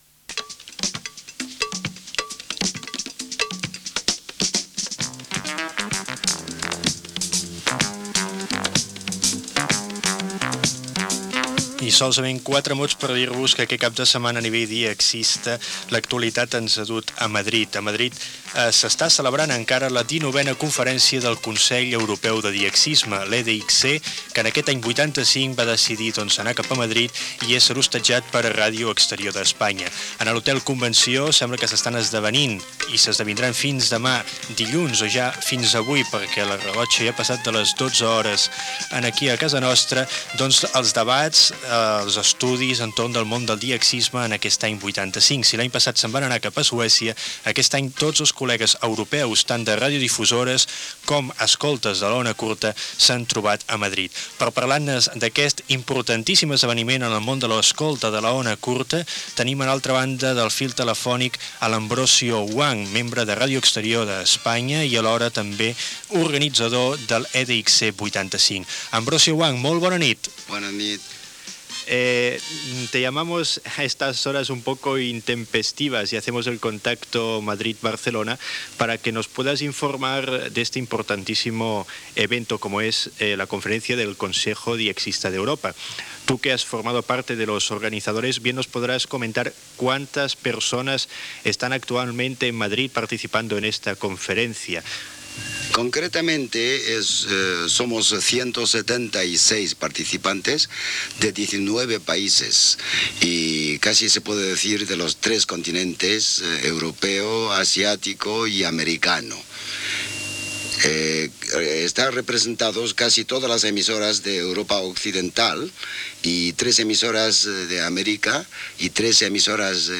Entrevista
discurs inaugural